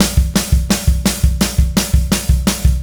Indie Pop Beat 01.wav